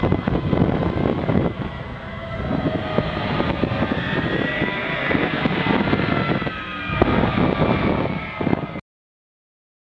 Звук пламени на ветру и что-то вроде ракет вдали:
fire2.wav